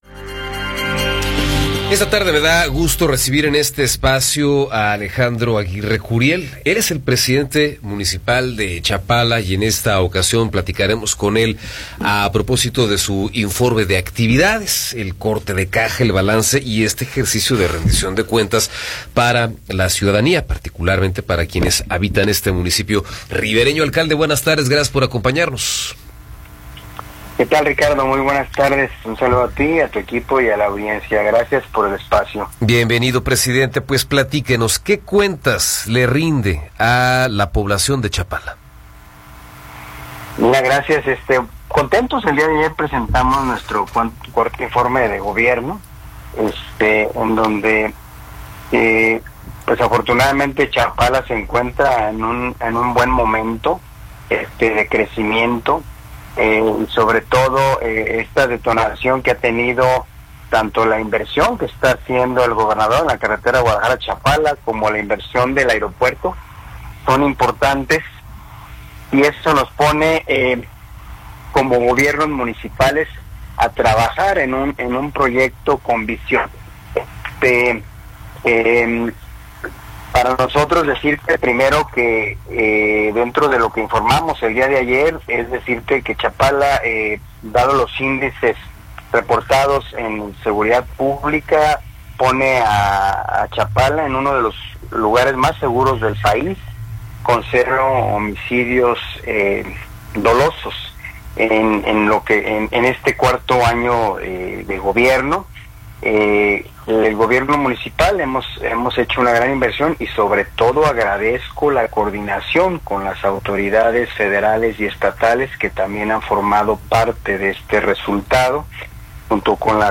Entrevista con Alejandro Aguirre Curiel